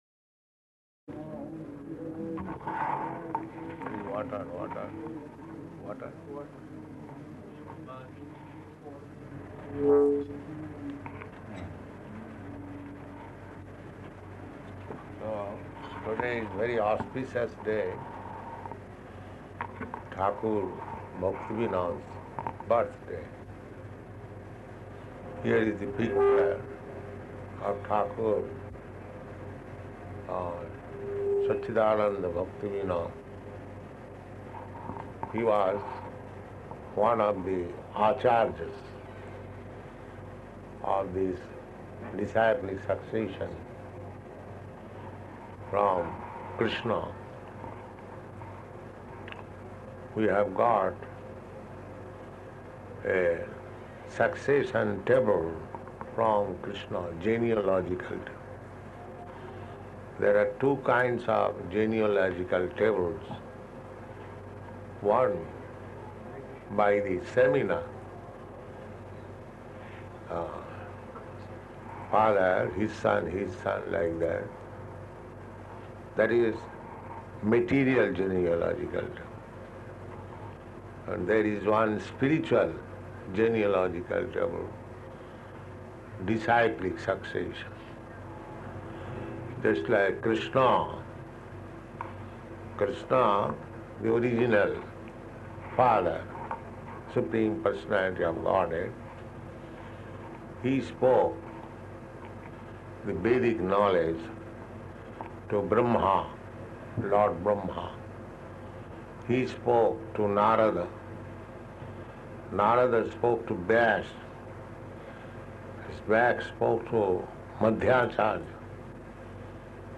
Śrīla Bhaktivinoda Ṭhākura's Appearance Day Lecture
Śrīla Bhaktivinoda Ṭhākura's Appearance Day Lecture --:-- --:-- Type: Appearance and Disappearance days Dated: September 1st 1971 Location: London Audio file: 710903BA-LONDON.mp3 Prabhupāda: Bring water, water.